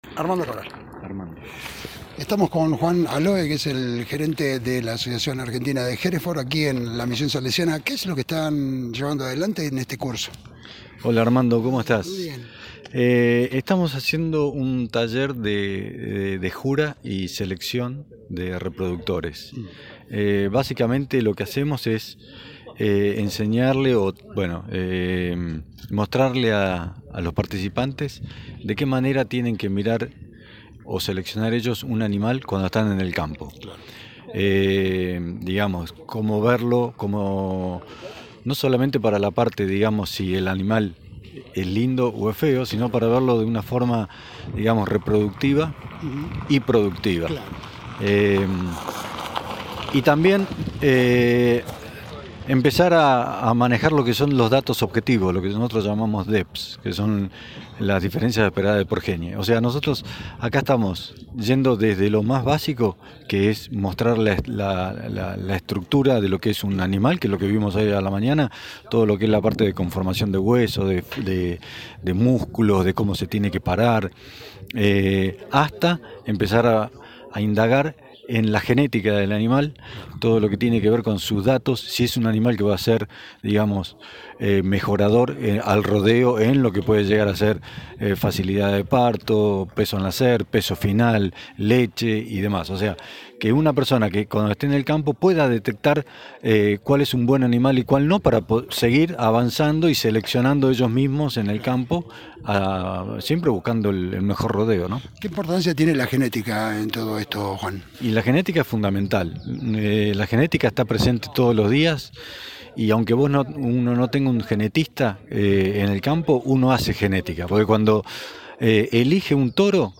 Fuente:Resumen Económico, Radio Provincia